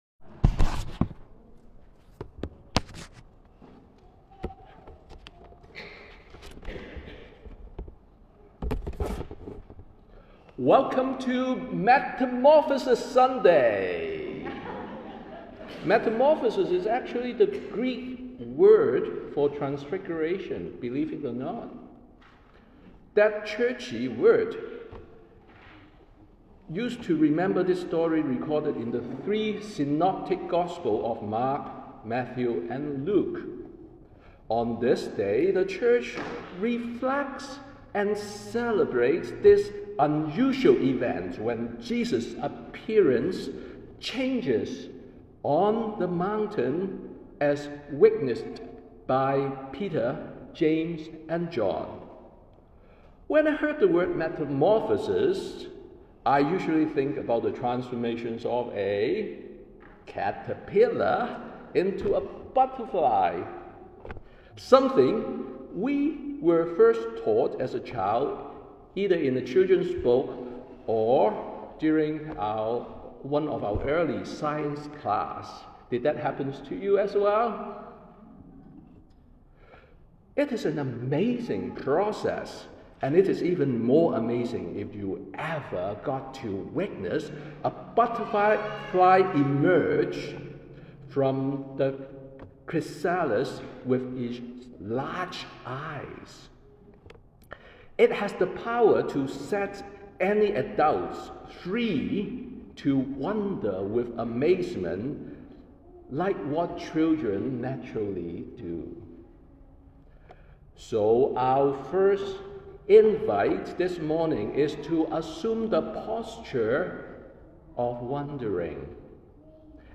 Sermon on the Last Sunday after Epiphany